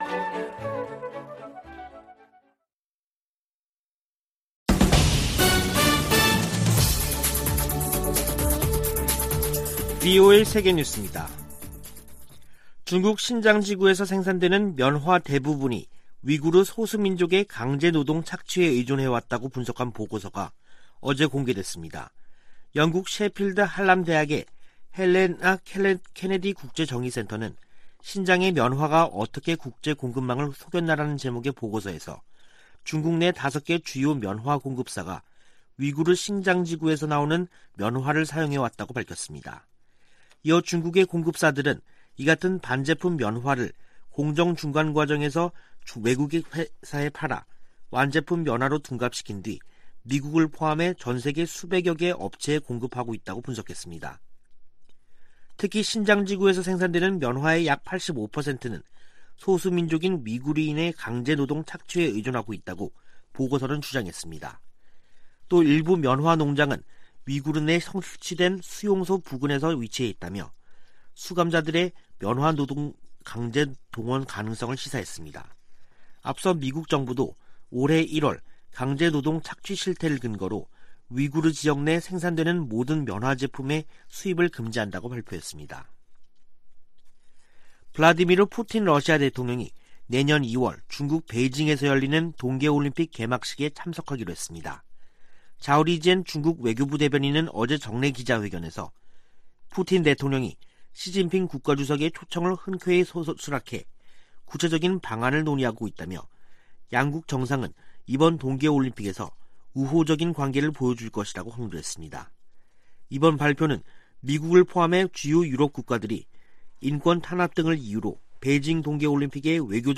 VOA 한국어 간판 뉴스 프로그램 '뉴스 투데이', 2021년 11월 24일 2부 방송입니다. 국제사회의 세부 안건들을 다루는 유엔총회 6개 위원회가 올해도 북한 문제를 놓고 각국이 설전을 벌였습니다. 미국 의회조사국이 북한의 사이버 공격을 진전되고 지속되는 위협으로 분류했습니다. 미국 국무부는 북한 당국이 최근 유엔의 북한인권 결의안 채택에 반발한 데 대해, 북한 인권 상황은 여전히 끔찍하다고 지적했습니다.